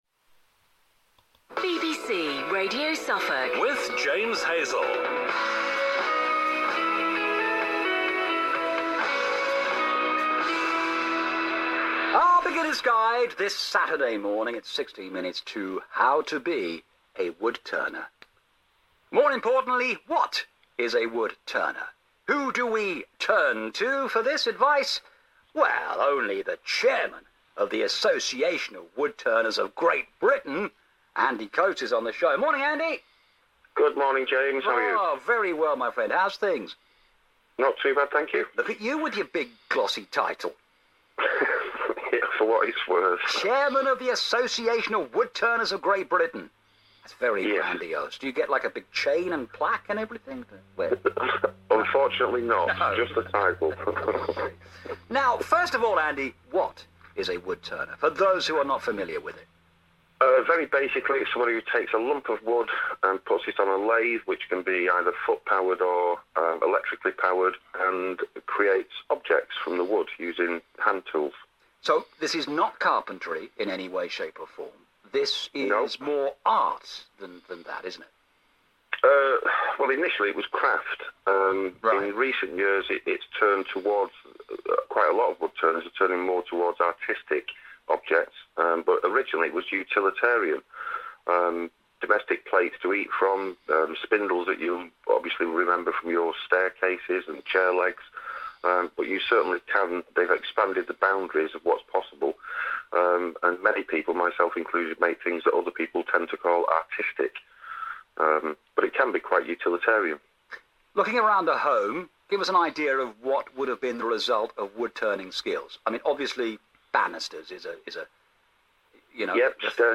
BBC Suffolk Interview May 2014